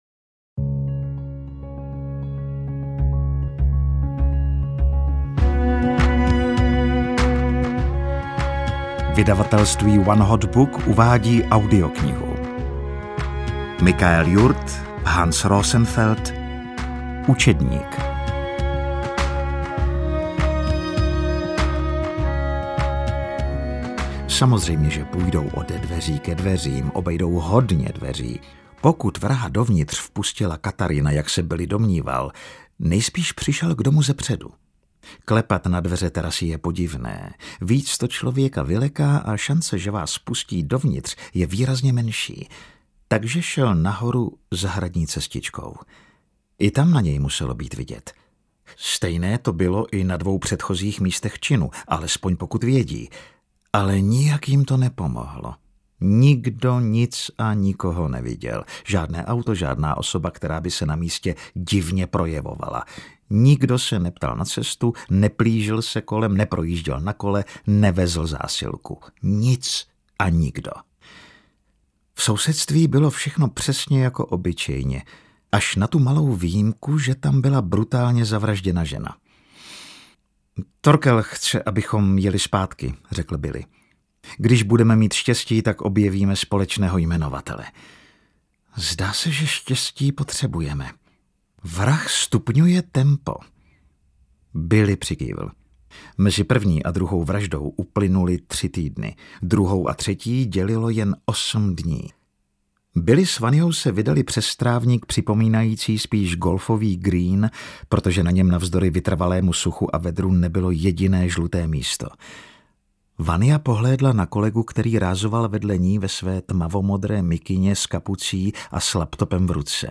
Učedník audiokniha
Ukázka z knihy
ucednik-audiokniha